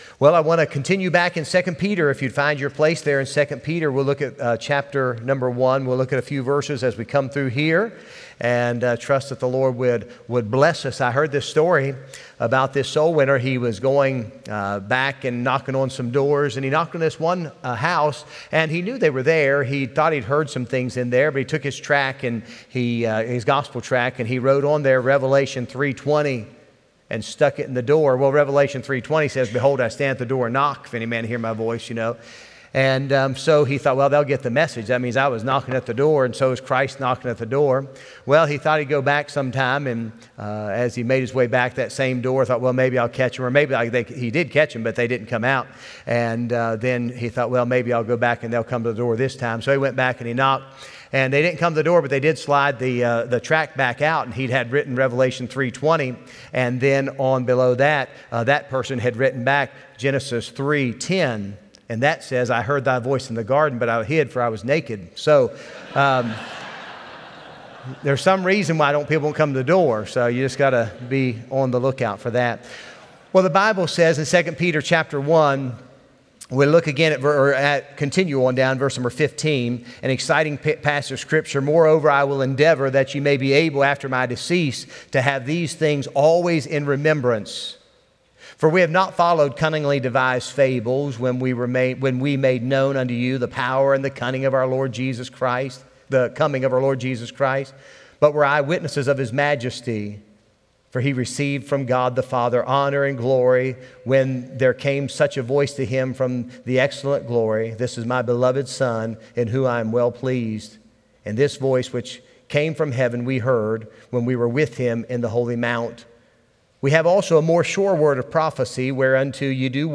Sunday Evening Service